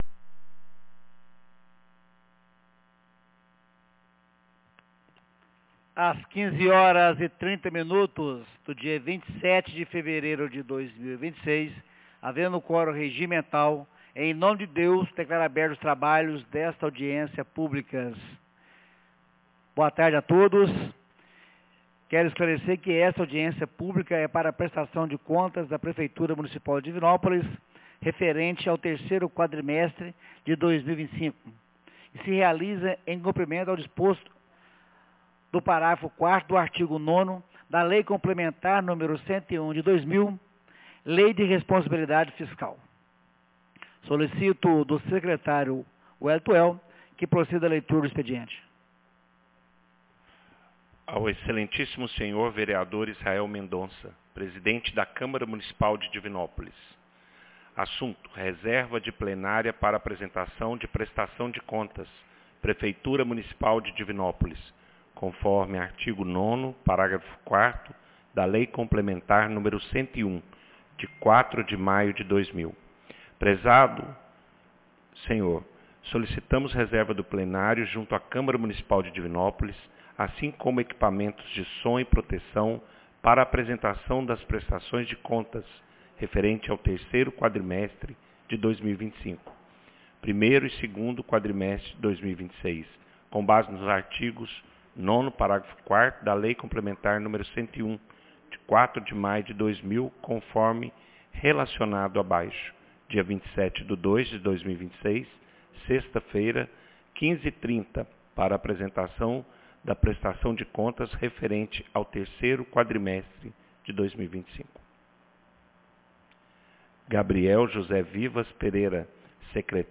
Audiencia Publica SemFaz Prestação de Contas 27 de fevereiro de 2026